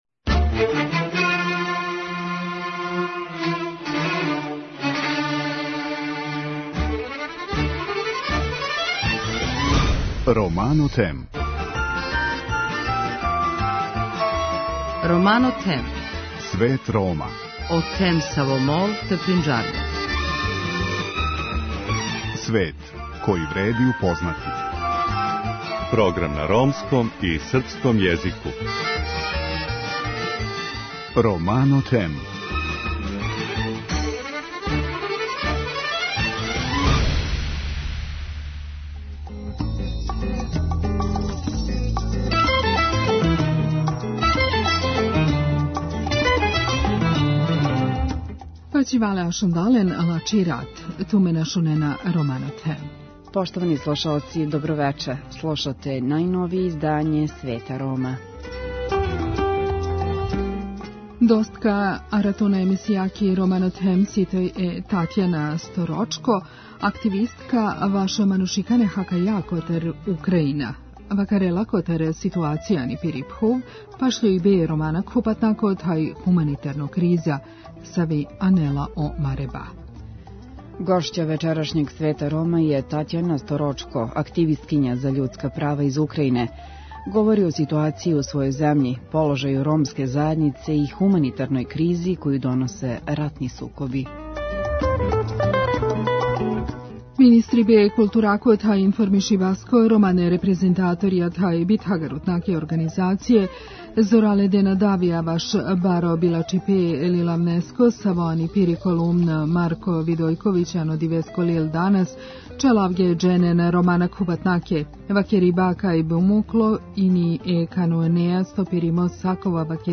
Аудио подкаст